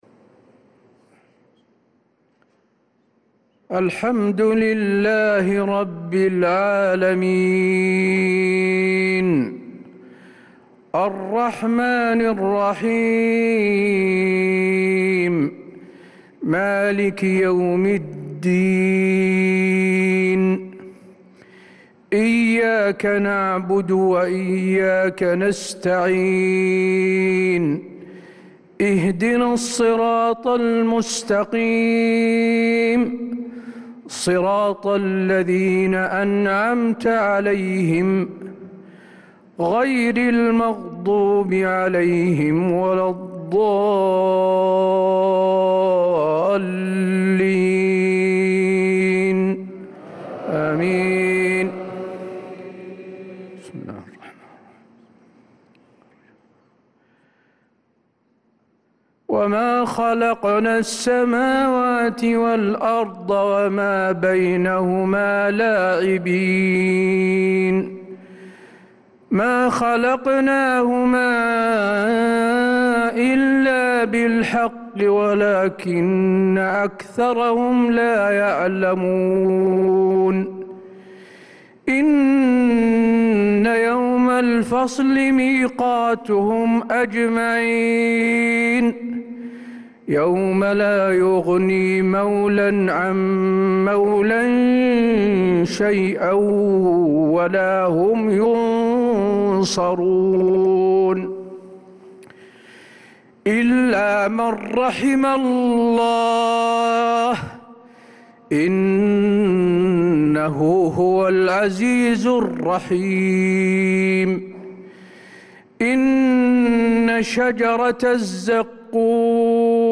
صلاة العشاء للقارئ حسين آل الشيخ 14 ذو القعدة 1445 هـ